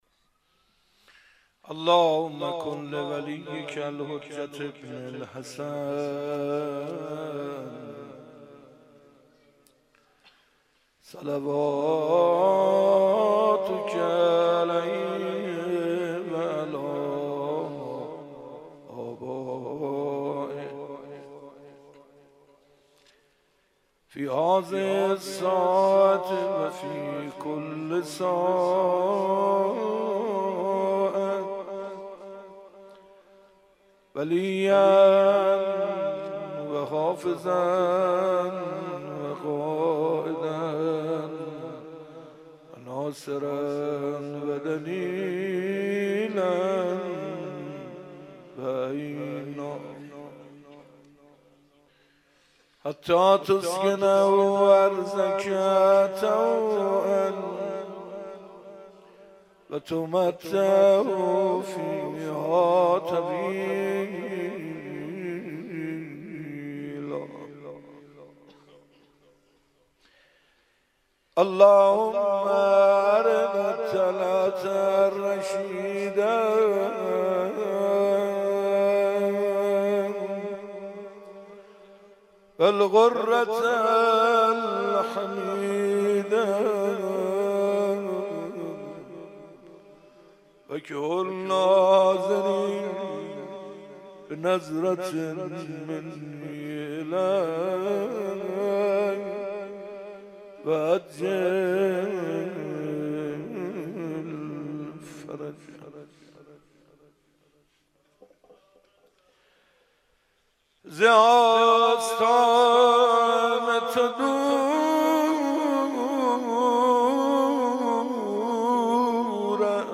25 محرم 97 - آستان مقدس امامزاده عبدالله - مناجات - دلم پر آشوب است